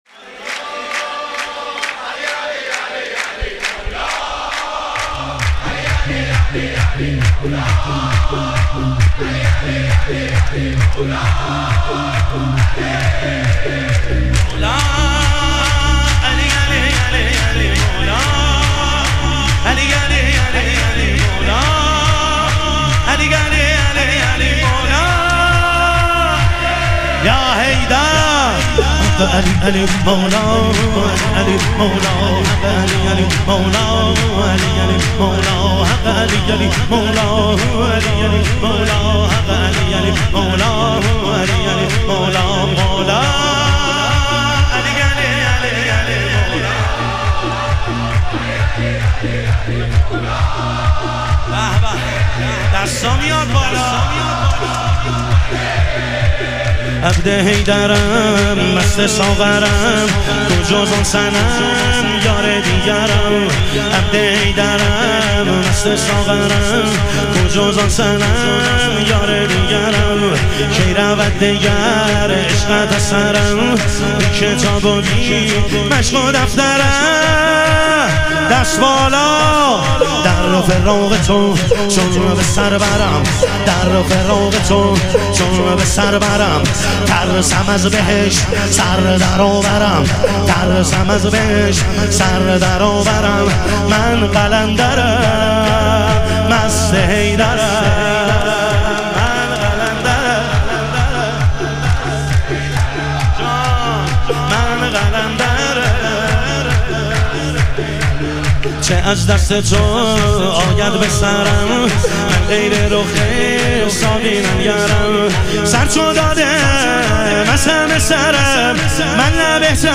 شور
شب ظهور وجود مقدس حضرت امیرالمومنین علیه السلام